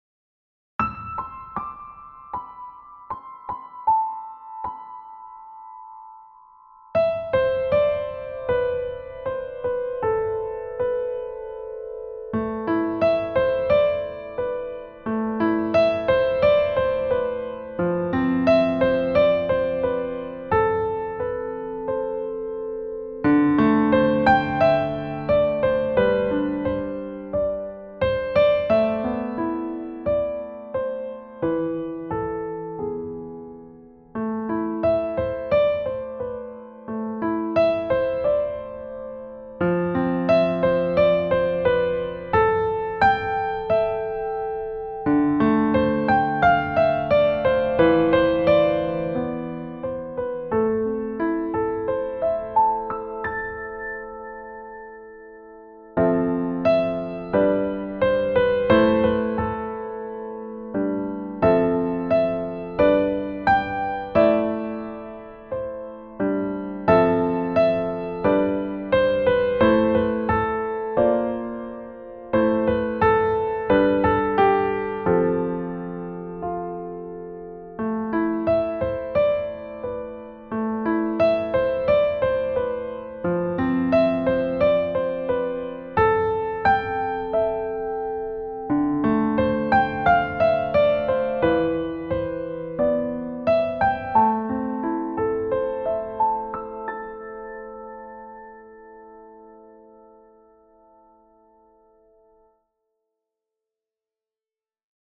Key: A minor
Time signature: 4/4
• Contrasting lyrical middle section with blocked 5ths